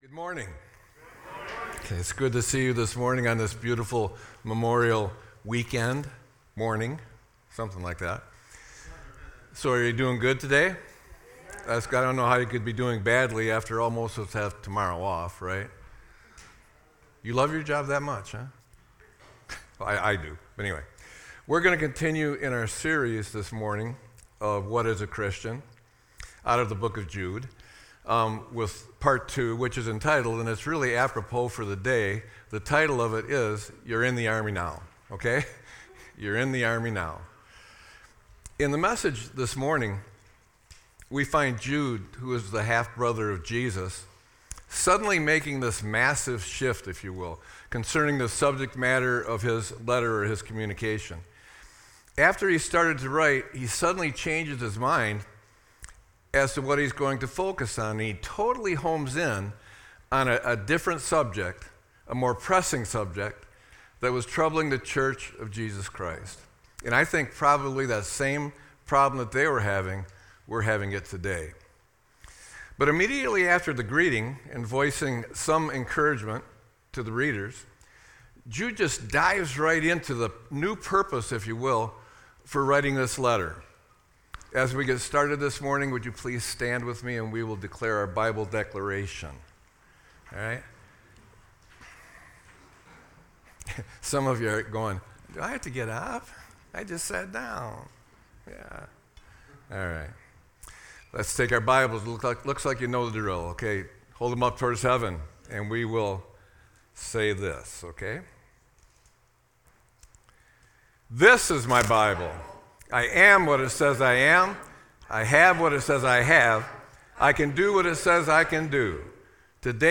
Sermon-5-25-25.mp3